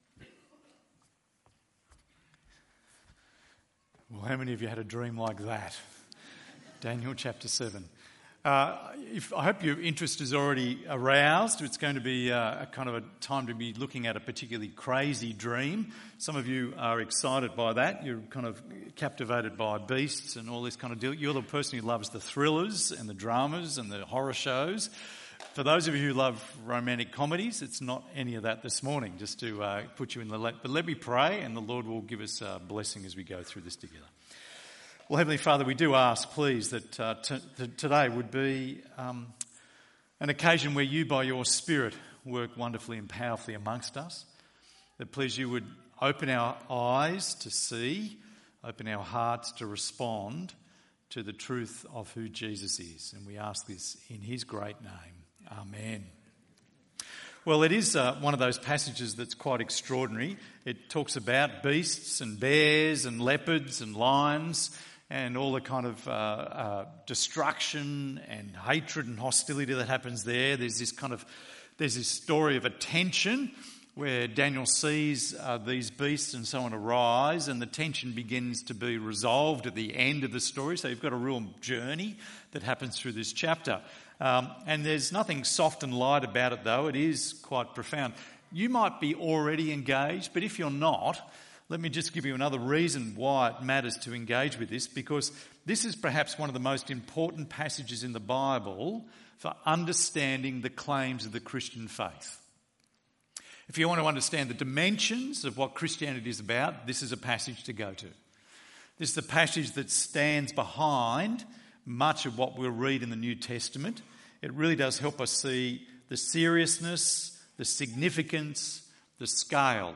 Religion